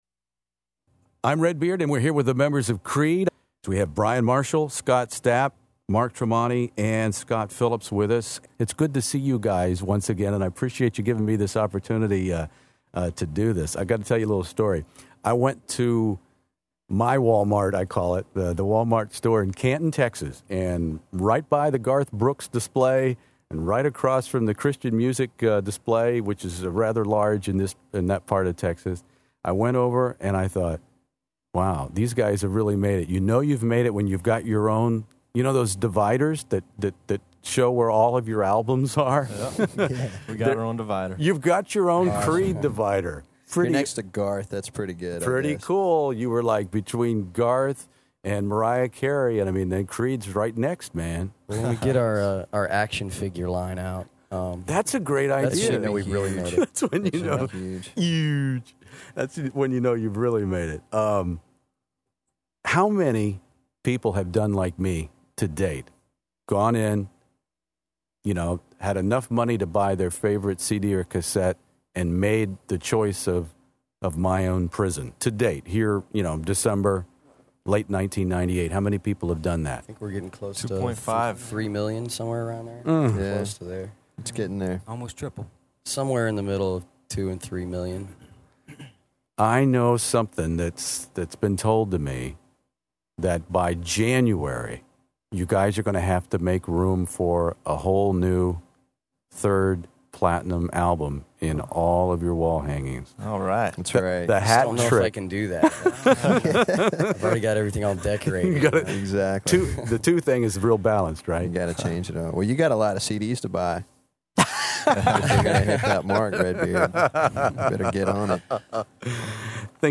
One of the world's largest classic rock interview archives, from ACDC to ZZ Top, by award-winning radio personality Redbeard.
On My Own Prison‘s twenty-fifth anniversary of “Torn”,”What’s This Life For?”, “One”, and the title song, here is my charming conversation with lead singer/ lyricist Scott Stapp, guitarist/songwriter Mark Tremonti, drummer Scott Phillips, and original bass guitarist Brian Marshall from December 1998.